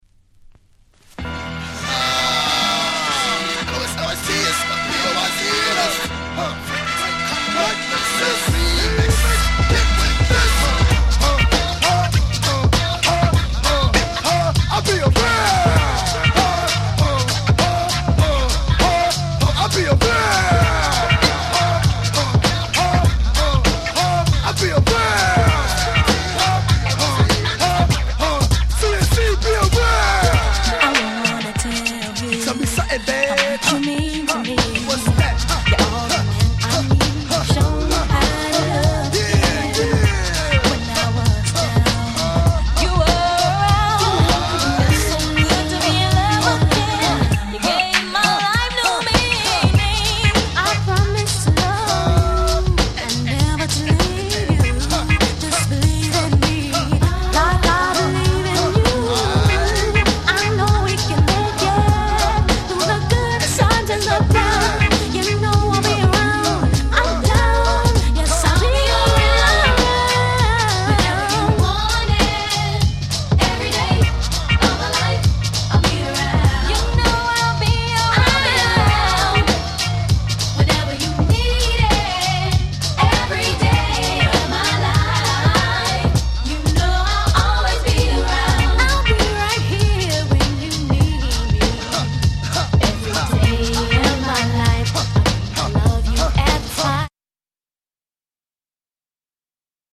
Rare Remix !!
90's US R&B Classic !!!